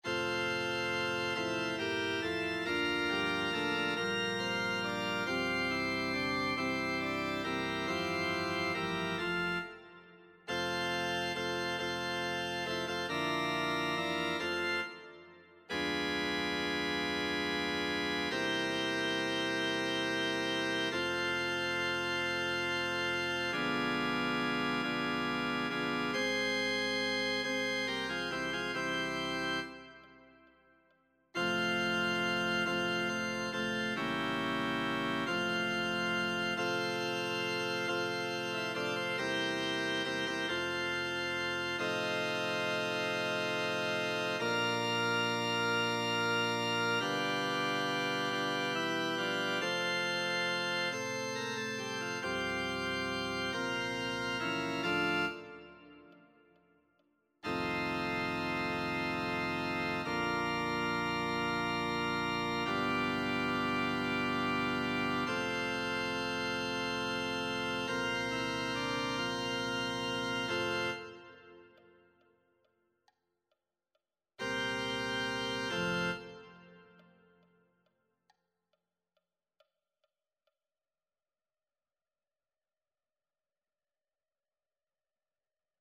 Singwoche pro Musica 2026 - Noten und Übungsdateien
Alle Stimmen